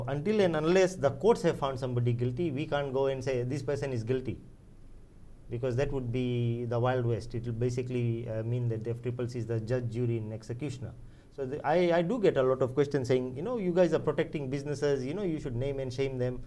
Speaking on the FBC Show “Saqamoli Matters”